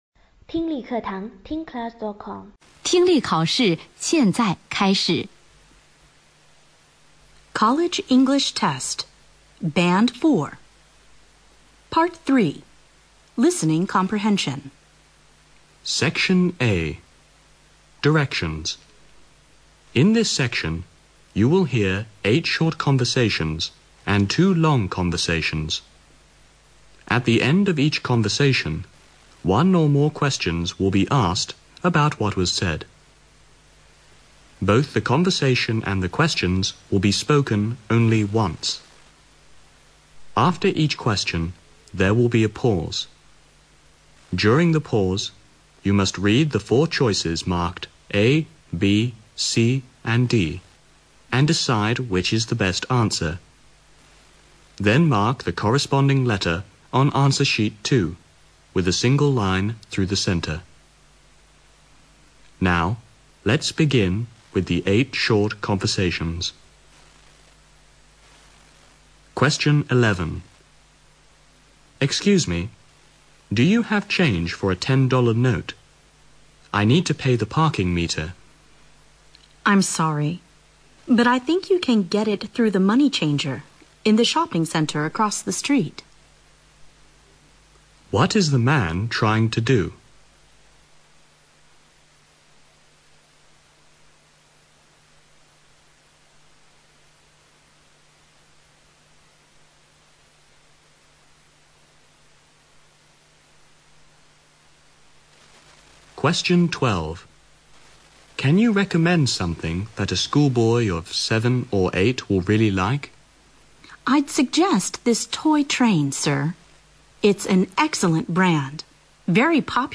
Part III Listening Comprehension